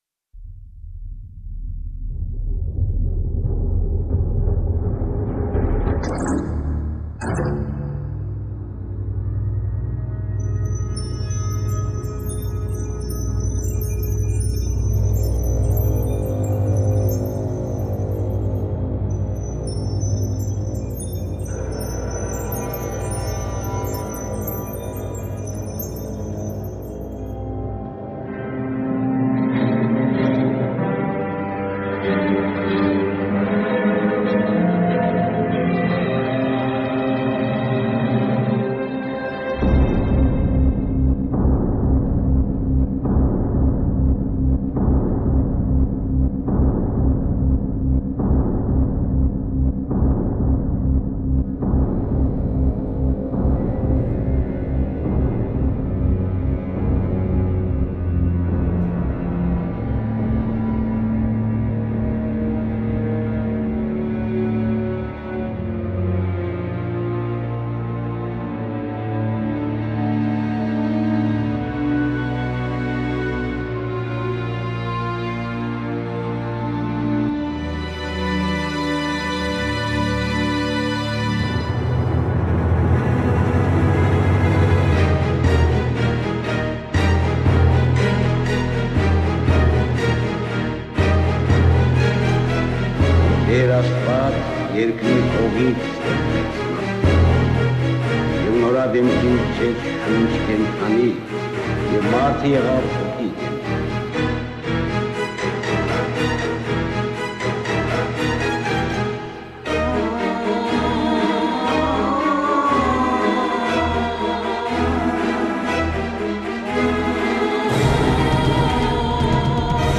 ルーマニア発、壮大なシンフォニック・ネオ・クラシカルサウンド
スペクタクル映画のサウンドトラック的作品です。
Mezzo-soprano Vocals